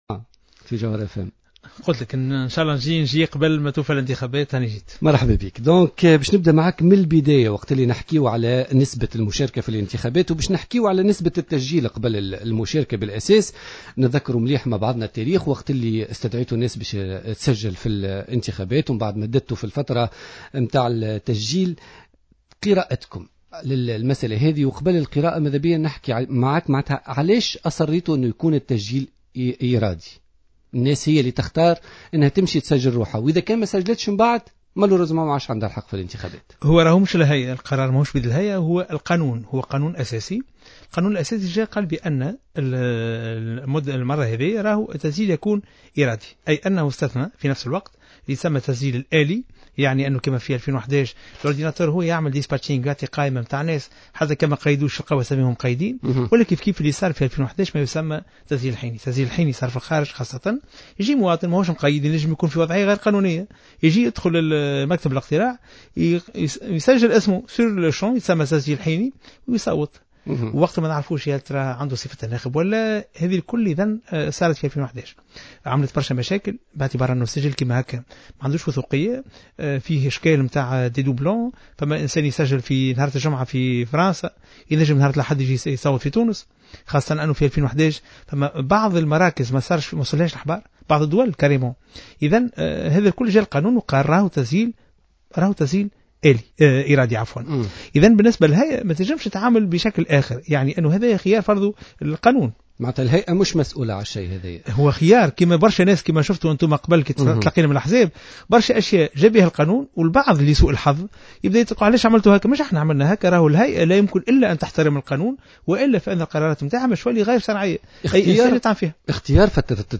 قال رئيس الهيئة العليا المستقلة للإنتخابات شفيق صرصار ضيف بوليتيكا اليوم الإثنين 1 ديسبمر 2014 إن تنظيم الإنتخابات في 43 دولة بالخارج ليس بالأمر الهين على دولة محدودة الموارد مثل تونس.